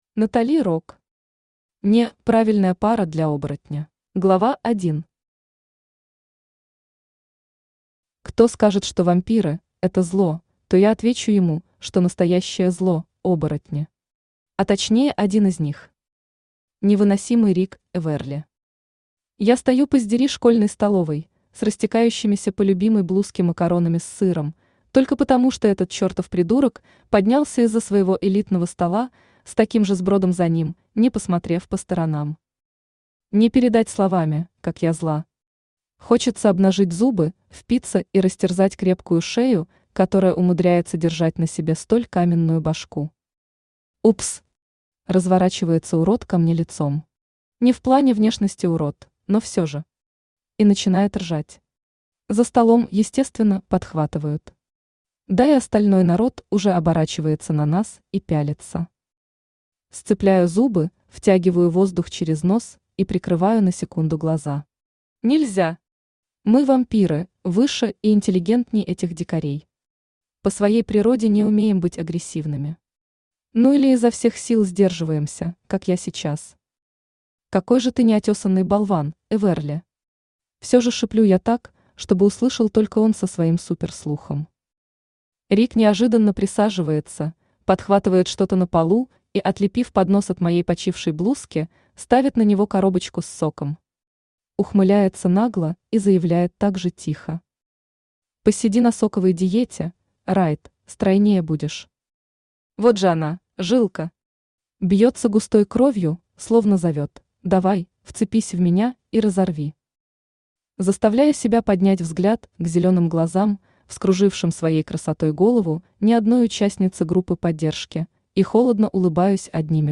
Аудиокнига (Не)Правильная пара для оборотня | Библиотека аудиокниг
Aудиокнига (Не)Правильная пара для оборотня Автор Натали Рок Читает аудиокнигу Авточтец ЛитРес.